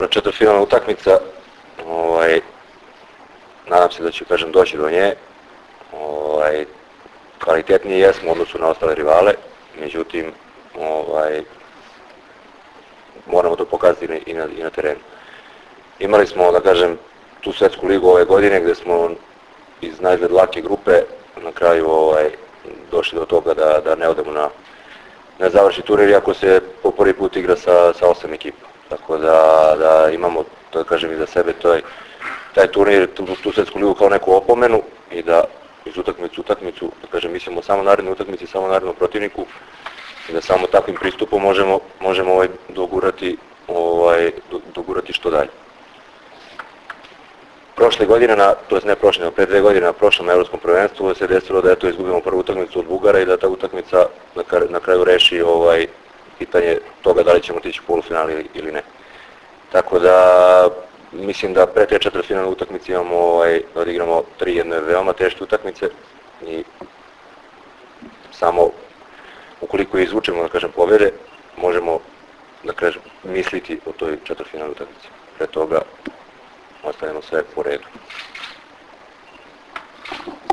Tim povodom, danas je u beogradskom hotelu “M” održana konferencija za novinare, kojoj su prisustvovali Igor Kolaković, Ivan Miljković, Dragan Stanković i Vlado Petković.
IZJAVA DRAGANA STANKOVIĆA